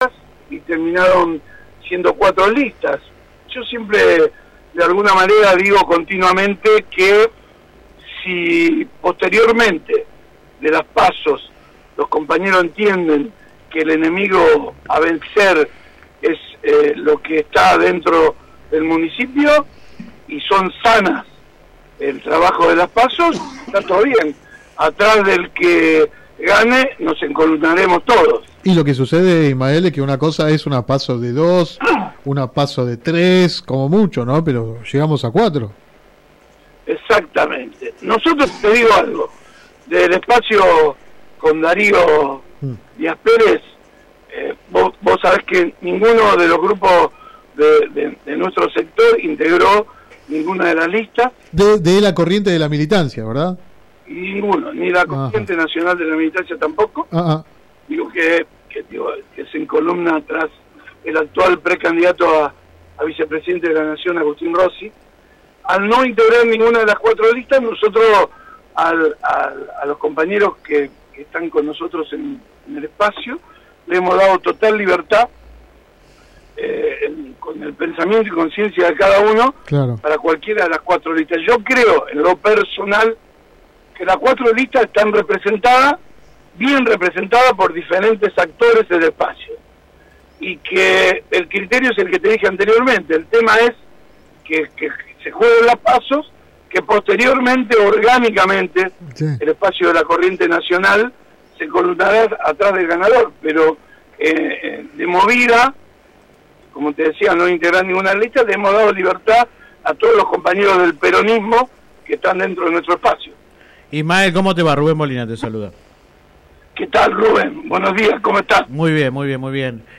Click acá entrevista radial https